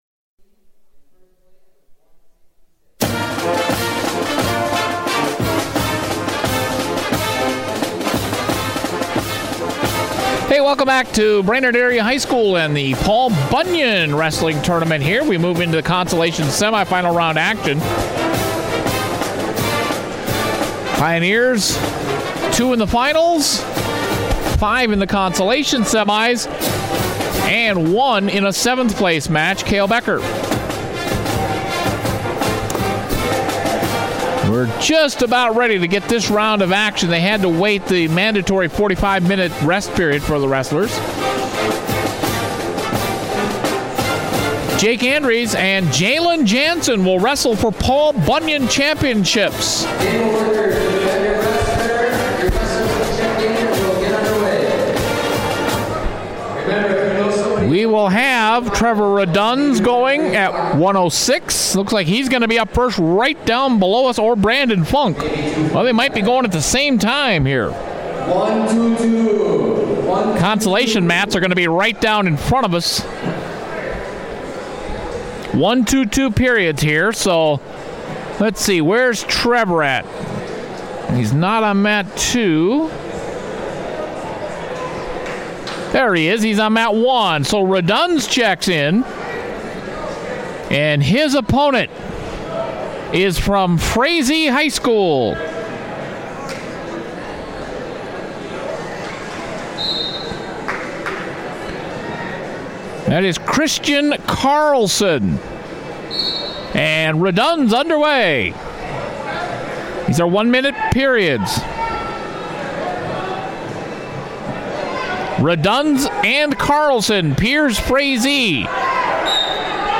Pierz Pioneers Wrestling at Paul Bunyan Tournament Consolation Semifinals 2017